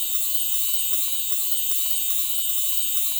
soft-sliderslide.wav